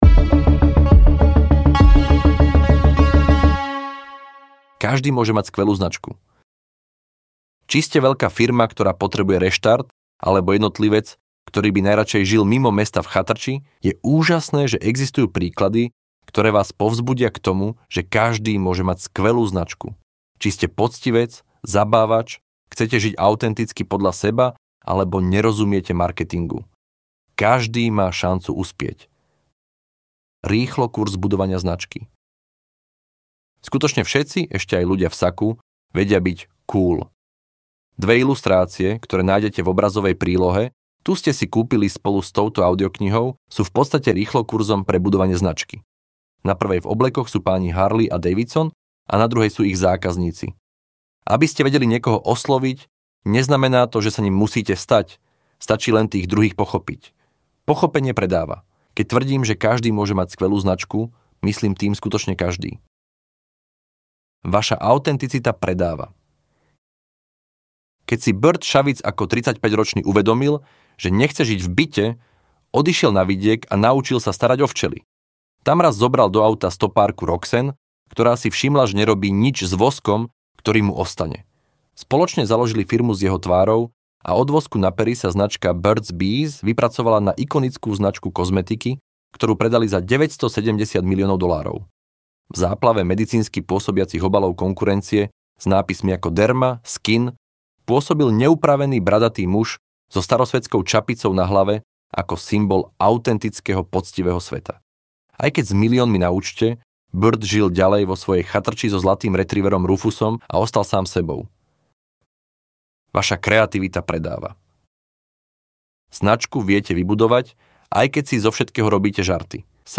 Ukázka z knihy
Kompletný zvukový obsah bol vytvorený pomocou umelej inteligencie.